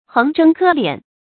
橫征苛斂 注音： ㄏㄥˋ ㄓㄥ ㄎㄜ ㄌㄧㄢˇ 讀音讀法： 意思解釋： 指濫收捐稅，強行搜刮民財。